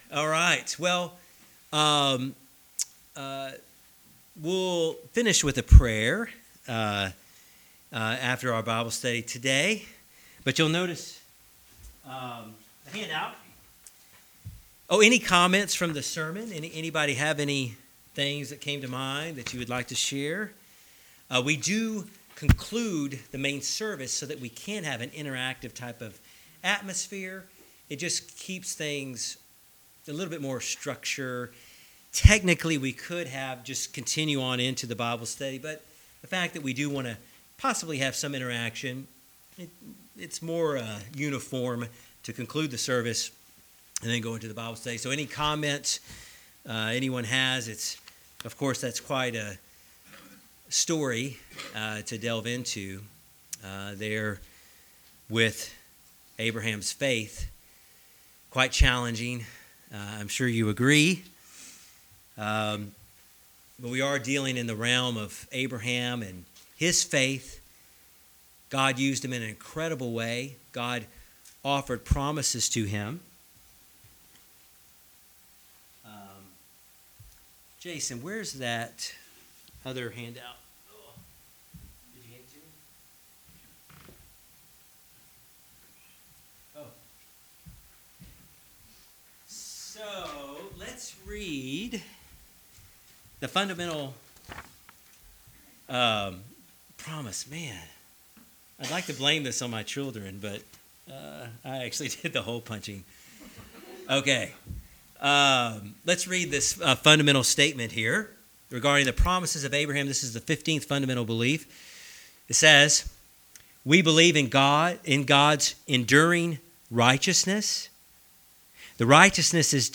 Bible Study--Fundamental Belief: God's Promises to Abraham. Discover what God said to and did for Abraham due to his faith and righteousness. Learn also what this means in terms of the opportunity of salvaion for all people through Jesus Christ.